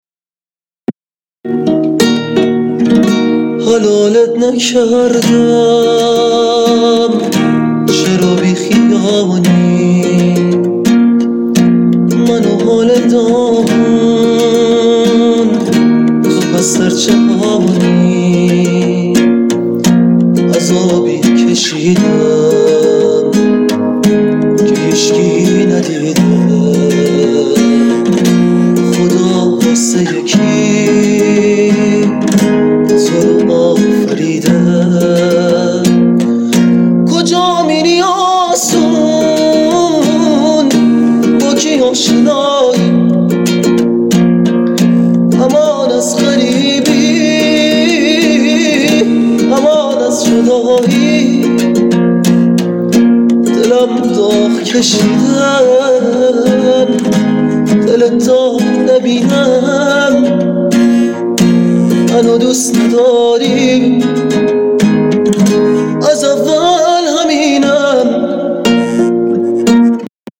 مثل همیشه صدای زیبای